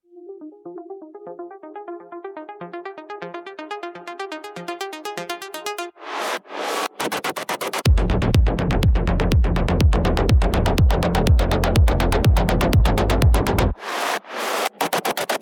Cyberpunk EDM